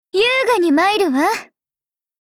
Cv-20509_battlewarcry_2.mp3 （MP3音频文件，总共长2.2秒，码率320 kbps，文件大小：87 KB）